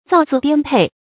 造次颠沛 zào cì diān pèi 成语解释 流离失所，生活困顿。